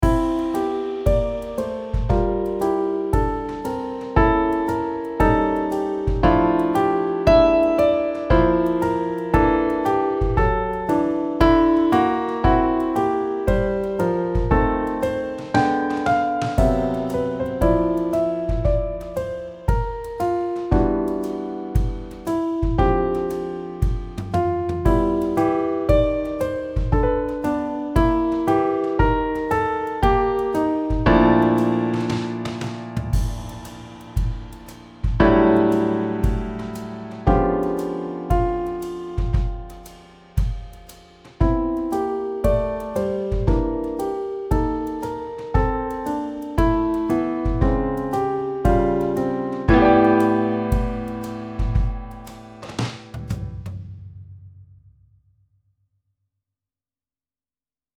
Für Klavier solo
Jazz/Improvisierte Musik
Klavier (1)